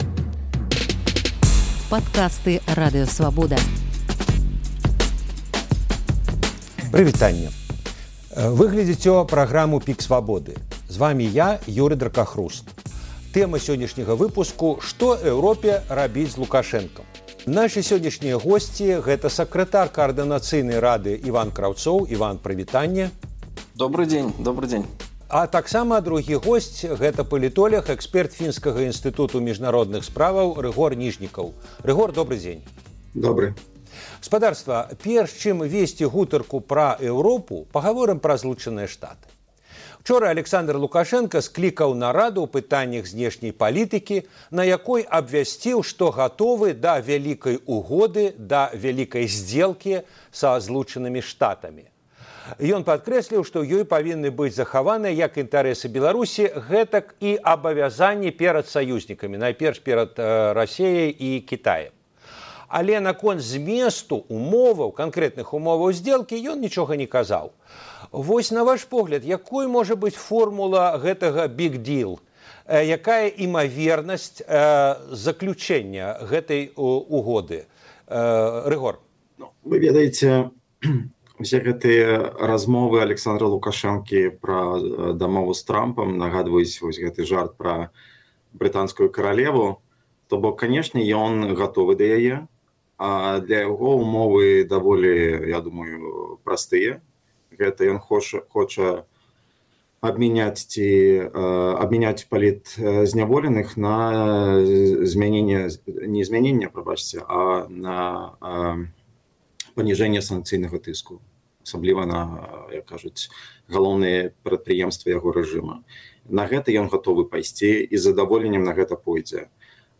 Ці варта Эўропе ісьці за Трампам да Лукашэнкі? Спрэчка